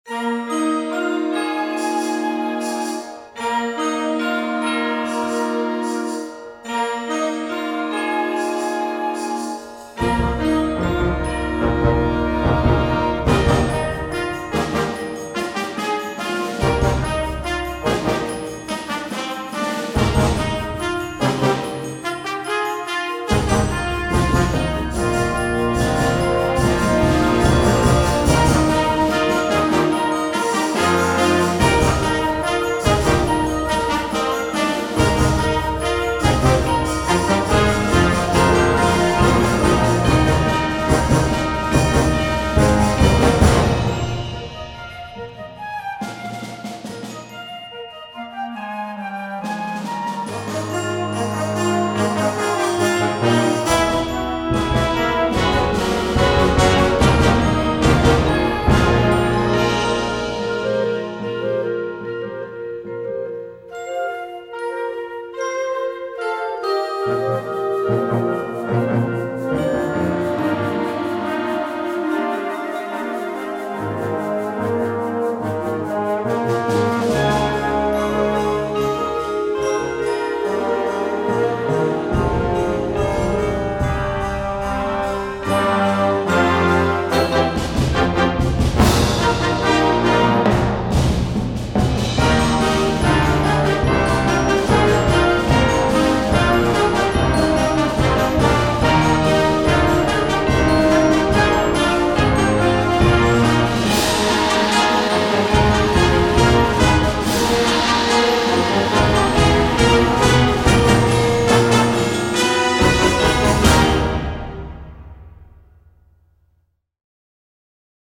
Gattung: Eröffnungswerk für Jugendblasorchester
Besetzung: Blasorchester